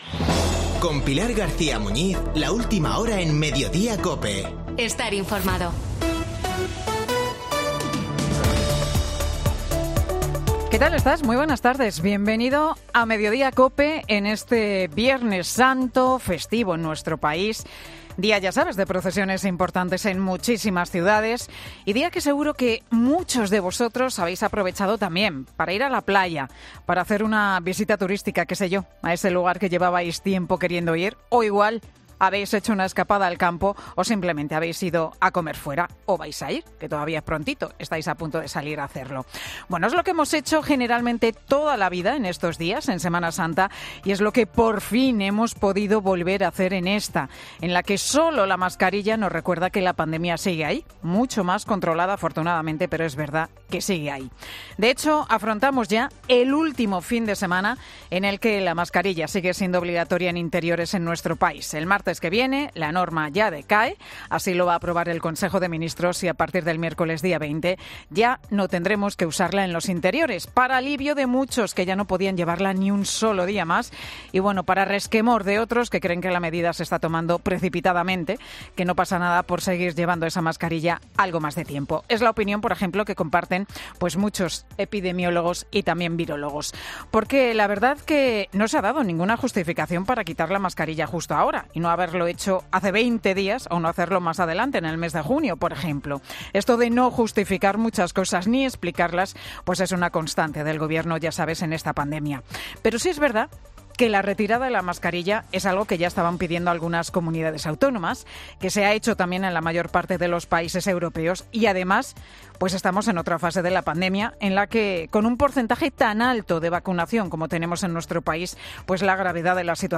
AUDIO: El monólogo de Pilar García Muñiz en Mediodía COPE